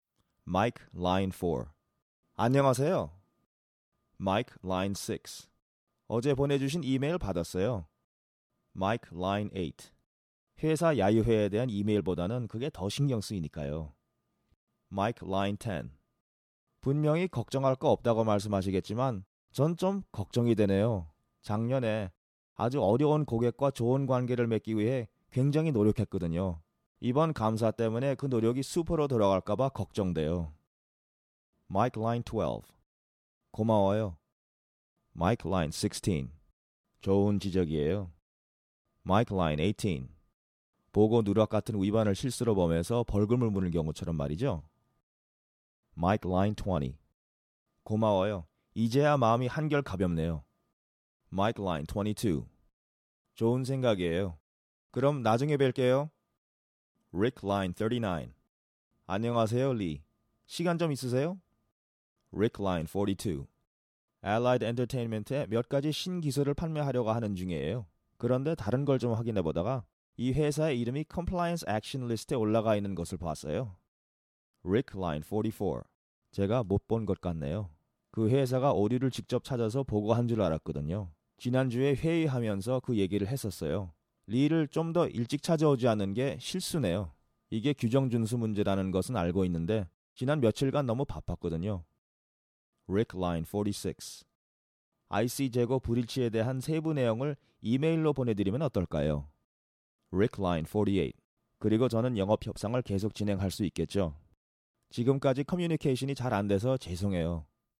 Warm and deep for narrations, smooth, professional and powerful for commercials and presentations. Additional accents or dialects: Korean language with slight Western accent, English with Asian accent.
Sprechprobe: eLearning (Muttersprache):